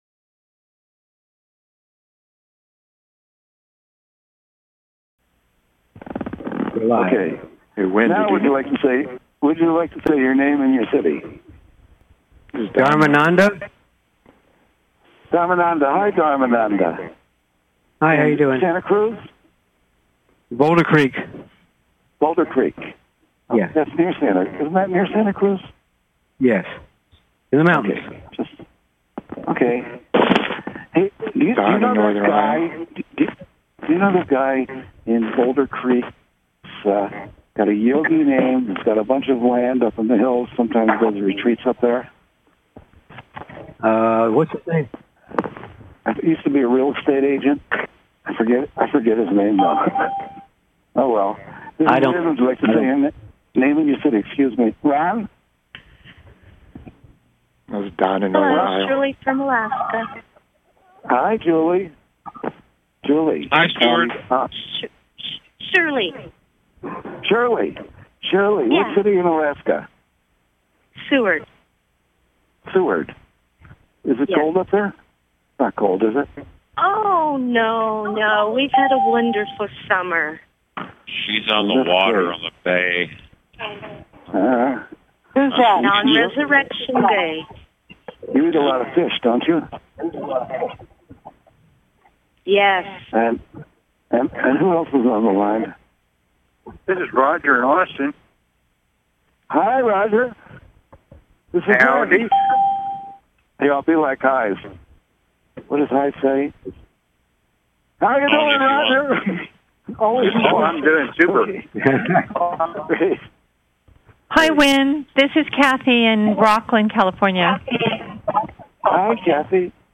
Talk Show Episode, Audio Podcast, You_Got_Questions_We_Got_Answers and Courtesy of BBS Radio on , show guests , about , categorized as